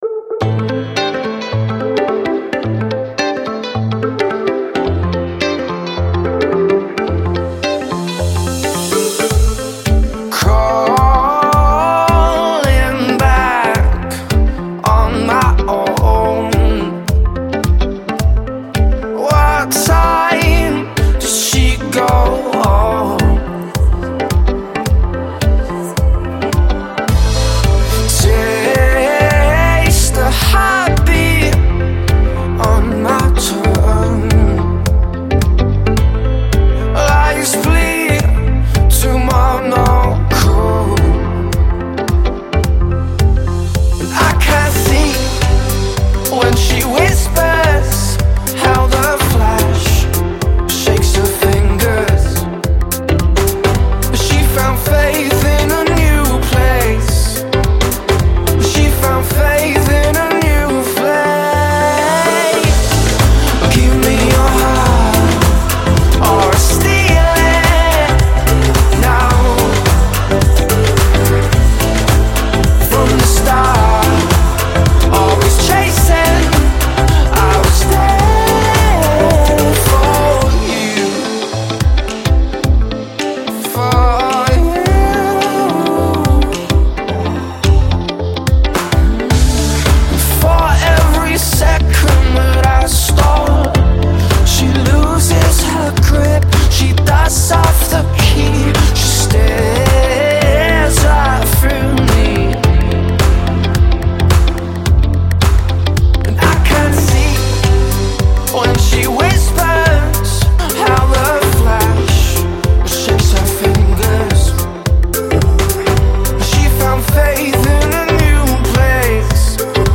Laid-back tropical grooves and taut guitars